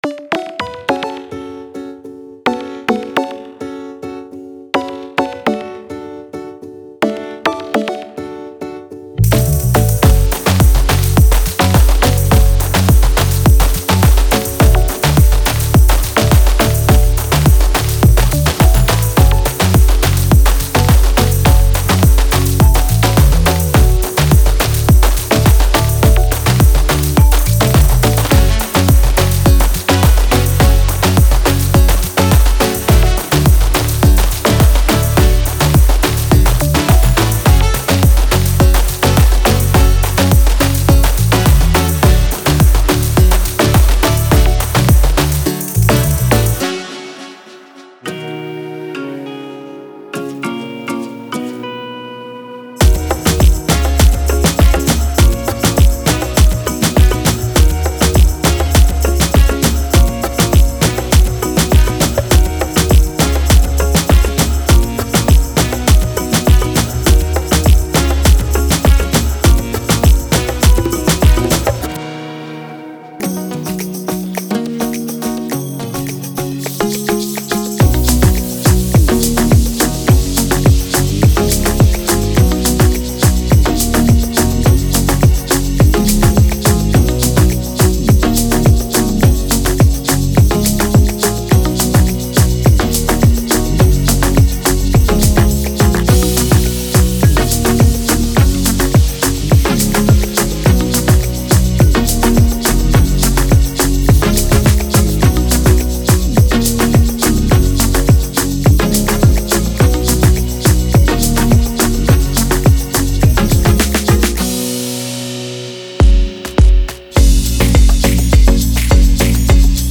クリーンで表情豊かなギターがメロディックなピアノやカリンバのループと組み合わされています。
柔らかなパッドと繊細なシンセレイヤーが横幅と雰囲気を加え、帯域を過剰に埋めることなく感情的な深みを高めます。
感情的で温かく、輝くメロディを持っています。
デモサウンドはコチラ↓
Genre:Future Pop
94 - 105 BPM